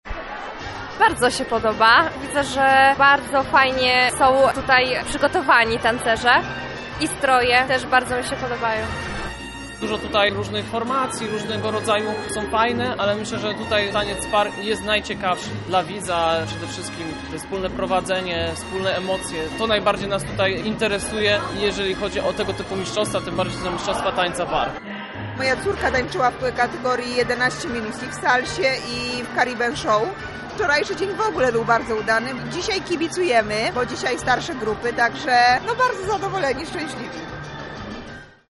Trybuny wypełnione były kibicami, którzy podzieli się z nami swoimi wrażeniami.